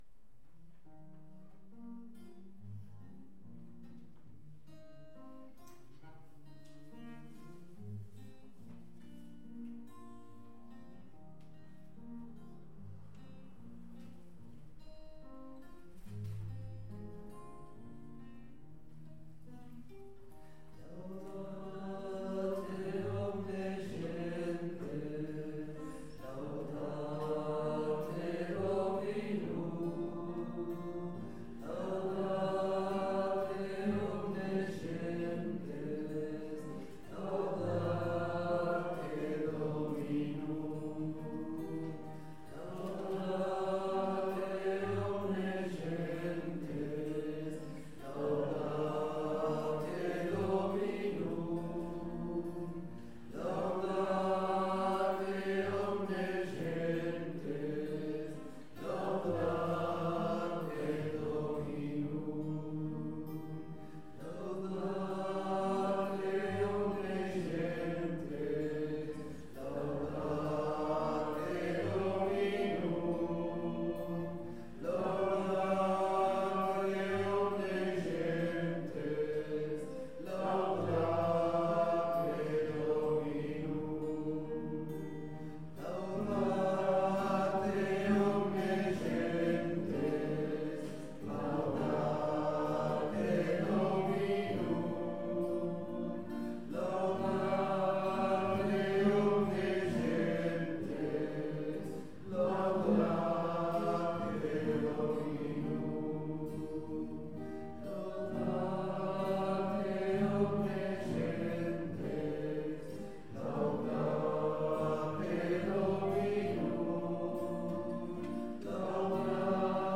Pregària de Taizé a Mataró... des de febrer de 2001
Parròquia Maria Auxiliadora - Diumenge 26 de març de 2023
Vàrem cantar...